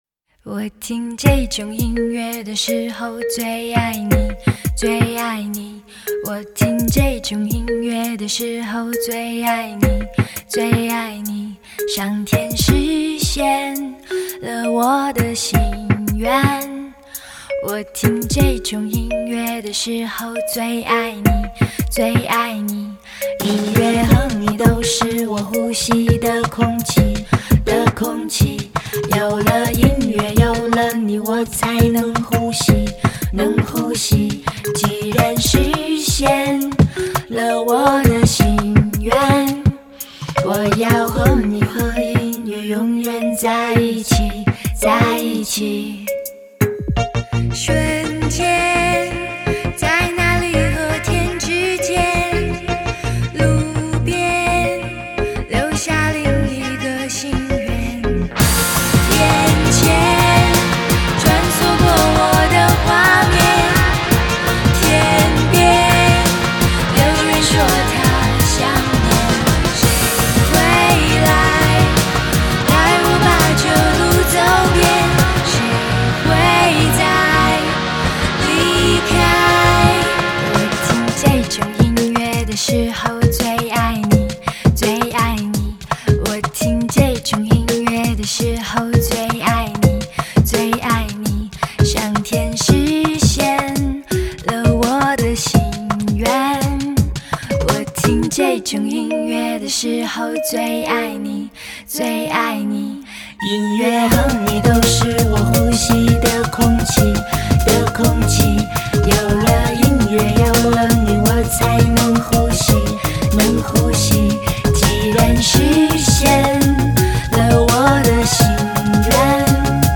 【类别】 内地流行